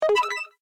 F1_New_SMS.ogg